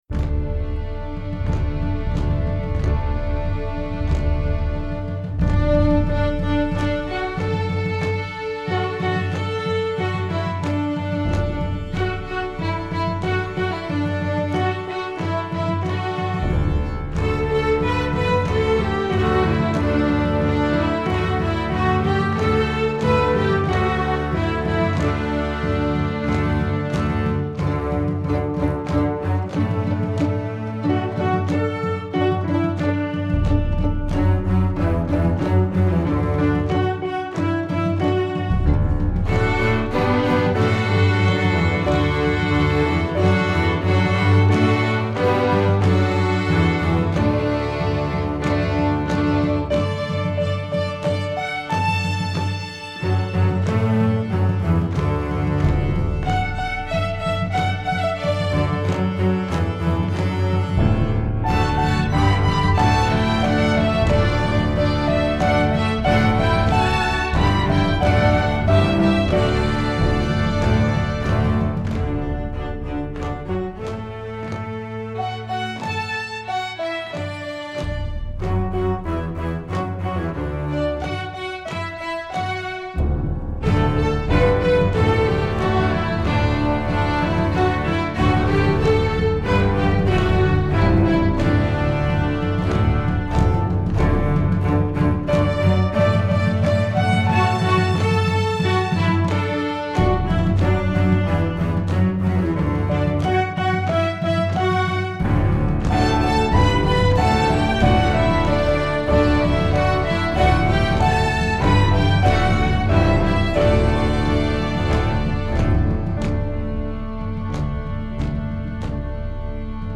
Icelandic Folk Song
String Orchestra